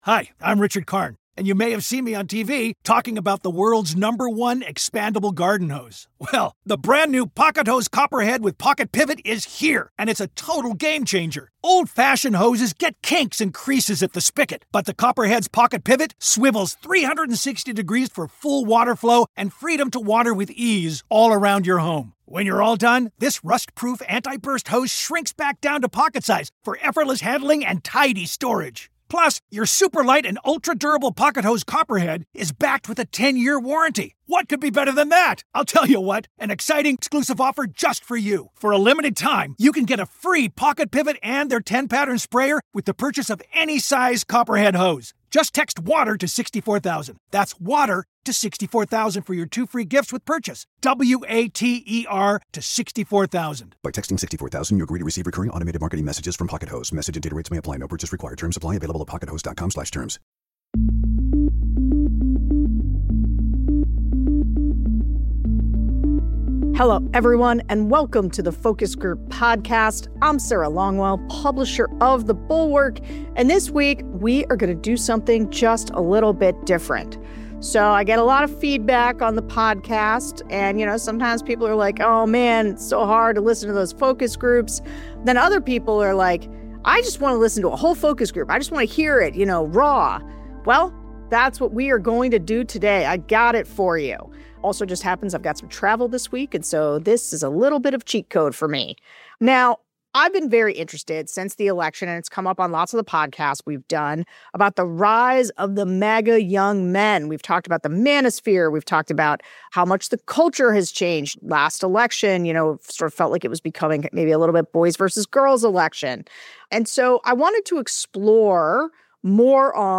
We're doing something different this week: We're giving you nearly an hour of audio from a recent focus group of men aged 18-29 who voted for Donald Trump in 2024.